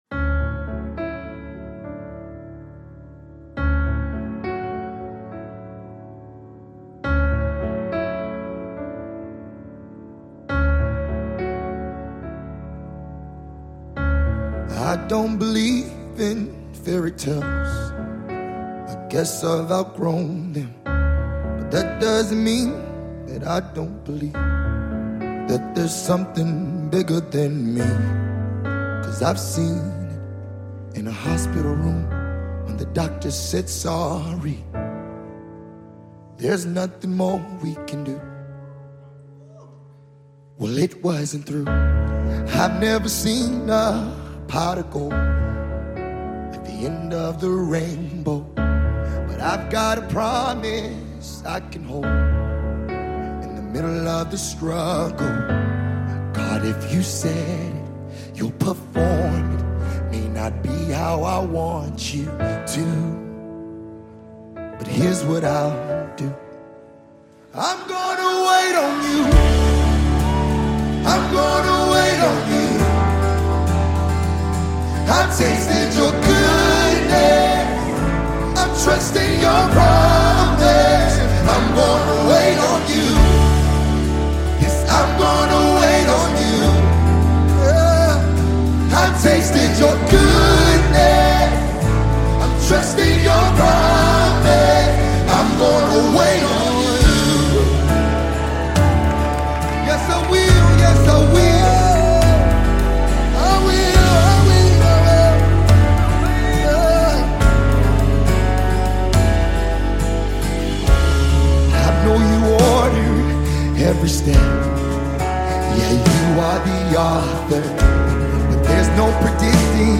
contemporary music team